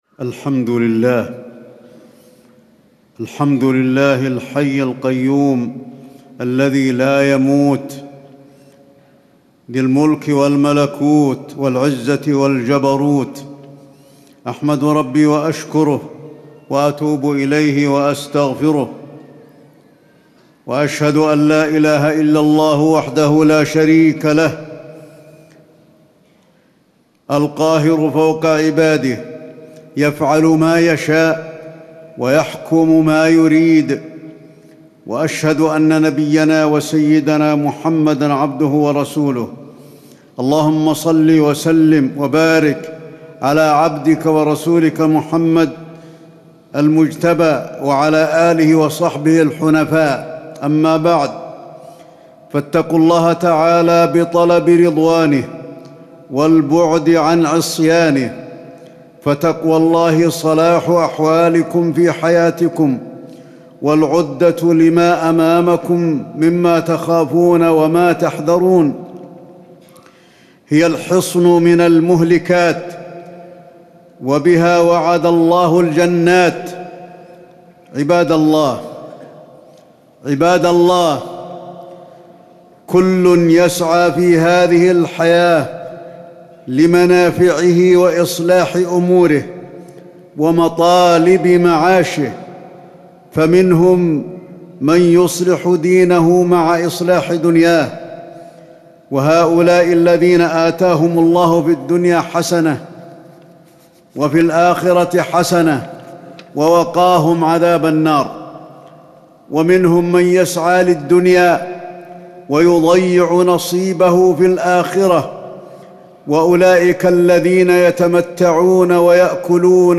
تاريخ النشر ١٦ جمادى الأولى ١٤٣٩ هـ المكان: المسجد النبوي الشيخ: فضيلة الشيخ د. علي بن عبدالرحمن الحذيفي فضيلة الشيخ د. علي بن عبدالرحمن الحذيفي الموت وكيف نستعد له